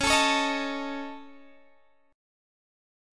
Listen to C#sus2#5 strummed